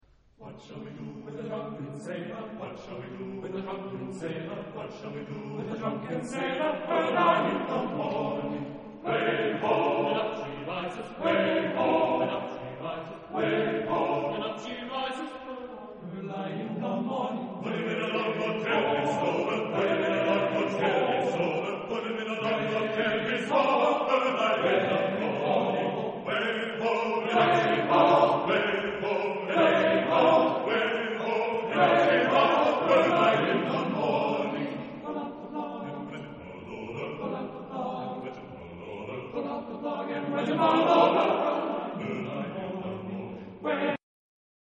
Arr.: Vazzana, Anthony (1922-2001) [ USA ]
Género/Estilo/Forma: Canción marinera ; Profano
Carácter de la pieza : humorístico
Tipo de formación coral: TTBB  (4 voces Coro de hombres )
Tonalidad : sol dórico ; re dórico